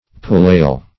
Pullail \Pul"lail\